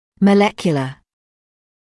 [mə’lekjələ][мэ’лэкйэлэ]молекулярный